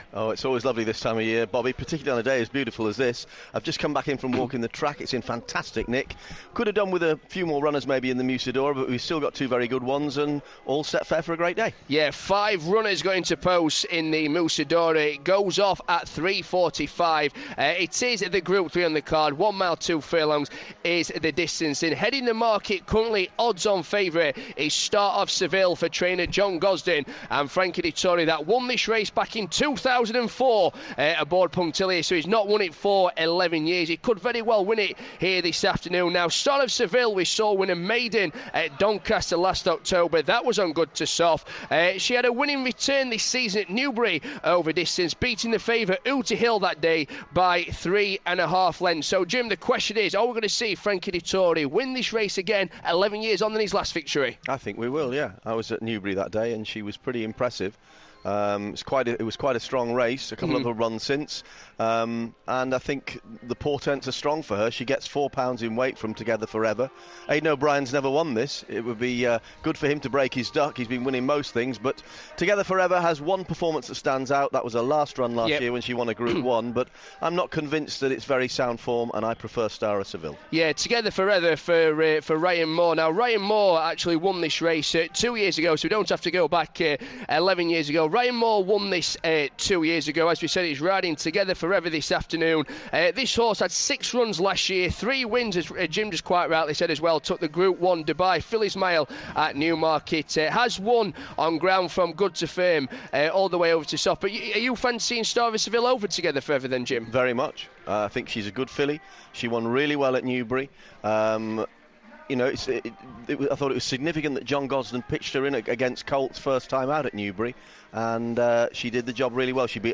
At York Races with Jim McGrath, Rishi Persad & Derek Thompson